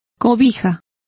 Also find out how cobija is pronounced correctly.